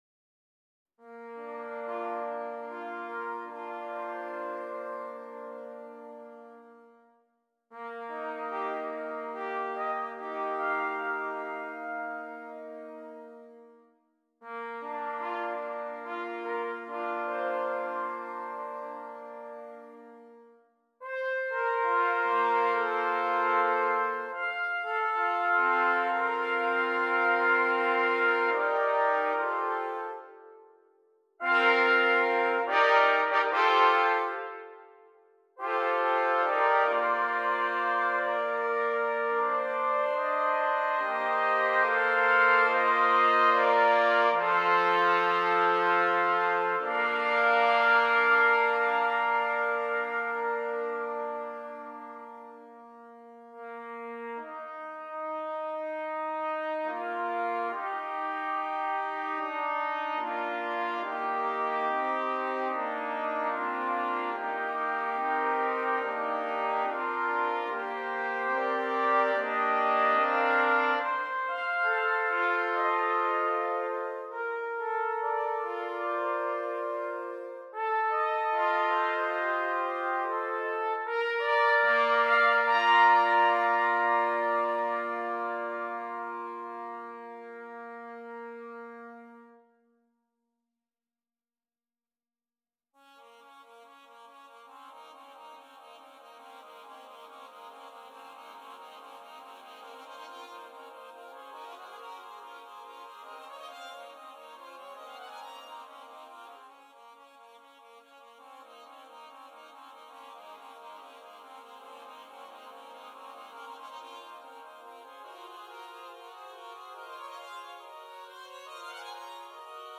4 Trumpets